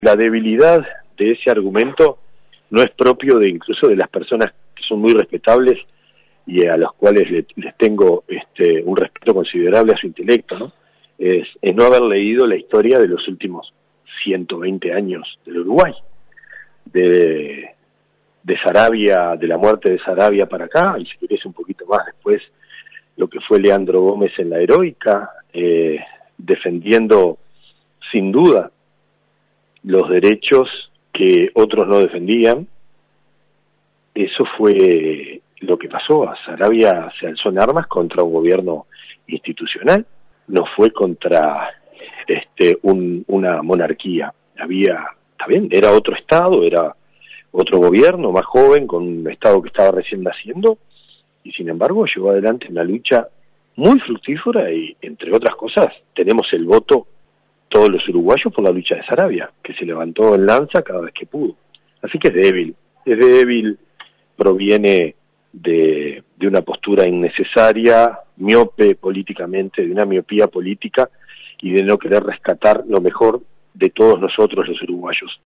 Gabriel Otero, diputado del Frente Amplio perteneciente al sector del MPP, defendió, en diálogo con 970 Noticias, la propuesta presentada en la reunión interpartidaria de homenajear a Raúl Sendic Antonaccio el 18 de marzo, fecha que se conmemora 100 años de su natalicio, y aseguró que “se va a votar”.